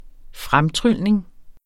Udtale [ -ˌtʁylˀneŋ ]